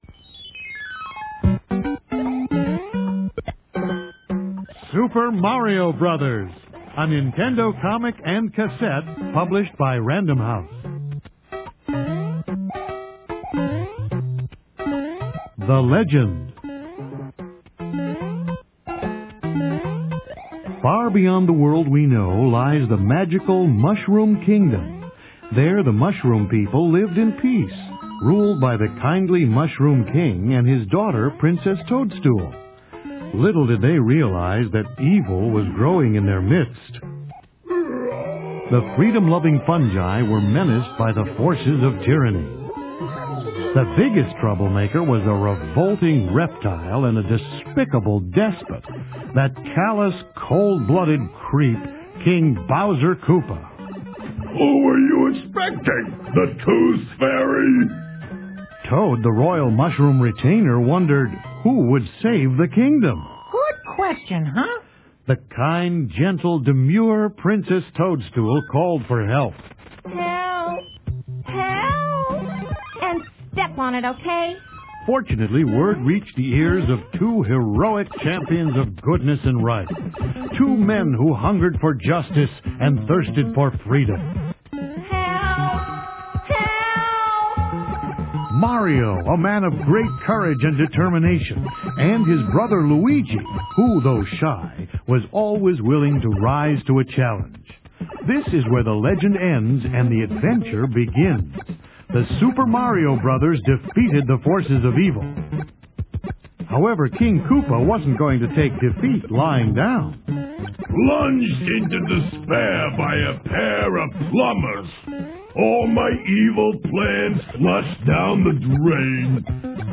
Published in 1991 by Random House, this was a packaged set that included a comic book featuring 4 stories reprinted from Valiant comics along with new cover art, and a specially produced audio tape that featured the same stories from the comic.
This audio was produced for children, so if you have kids, now is your chance to get them liking Valiant without even knowing it.